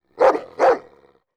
Dog_Large_barking_one_hit_2.wav